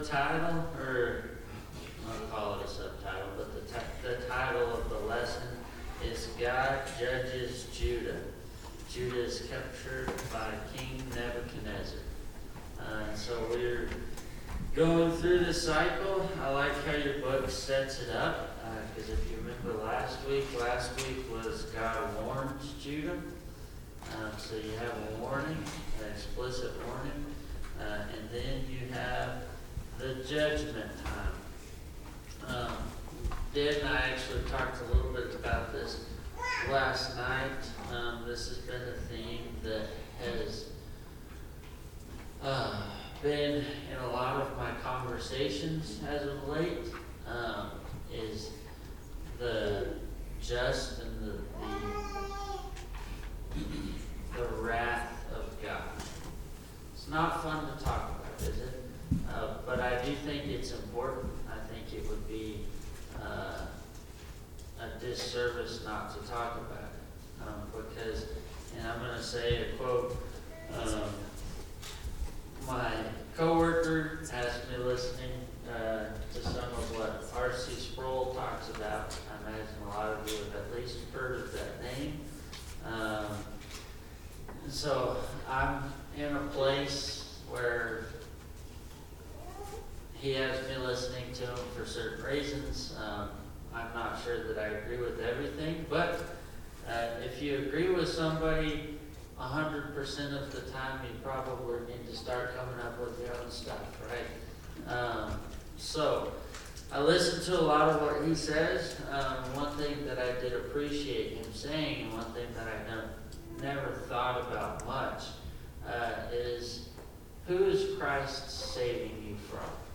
Bible Class 09/28/2025 - Bayfield church of Christ
Sunday AM Bible Class